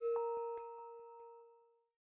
Folder_Open.ogg